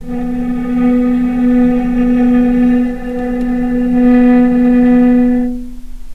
vc-B3-pp.AIF